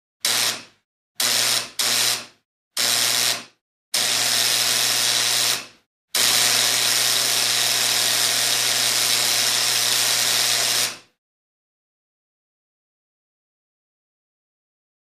Door Buzzer, Industrial Type; Loud, Midrange Bursts, Short And Long.